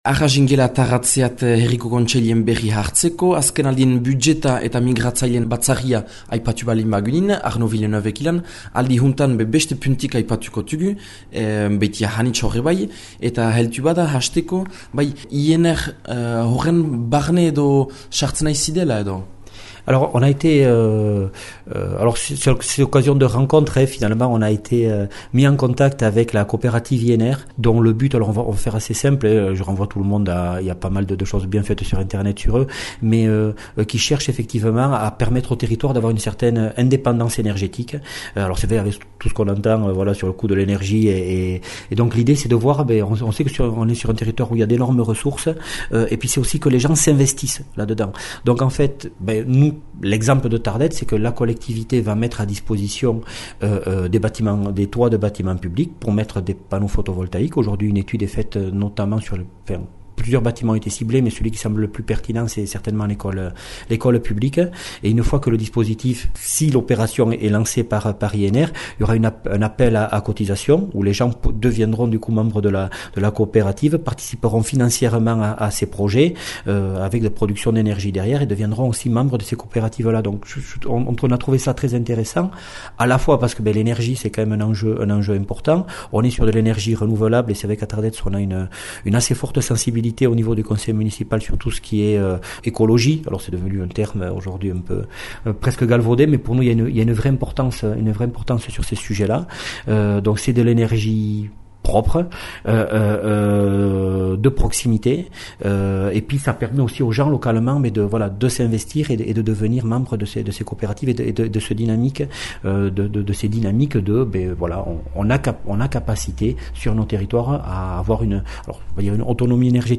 Jüntaren llabürpena Arnaud Villeneuve Atarratzeko aüzapezarekin: